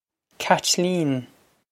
Caitlín Katch-leen
Pronunciation for how to say
This is an approximate phonetic pronunciation of the phrase.